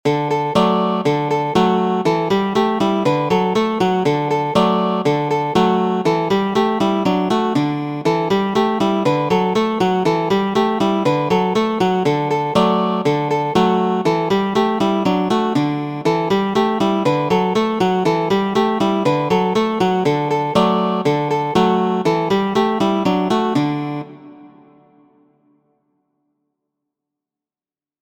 A já sám, ĉeĥa popolkanto ludita per japana koto pere de mi mem.